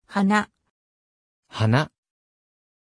Pronunciation of Hana
pronunciation-hana-ja.mp3